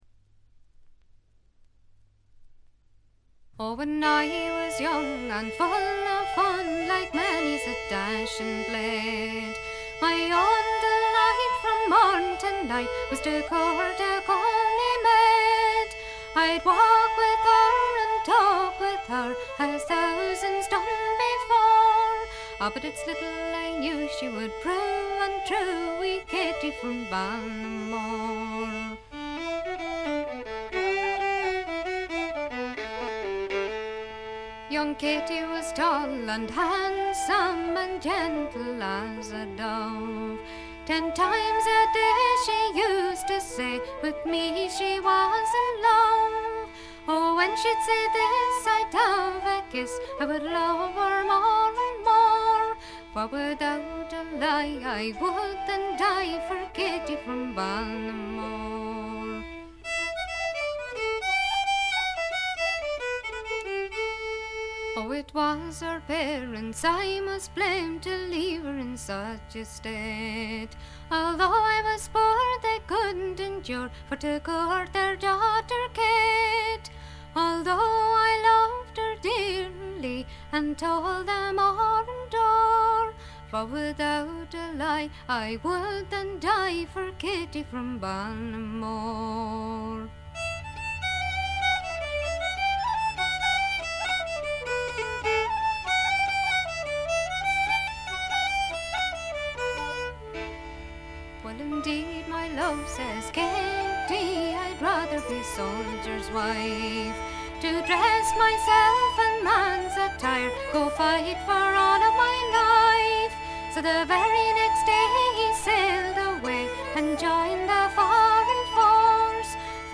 軽微なバックグラウンドノイズ程度。
時に可憐で可愛らしく、時に毅然とした厳しさを見せる表情豊かで味わい深いヴォーカルがまず最高です。
試聴曲は現品からの取り込み音源です。
vocals, harpsichord, bodhran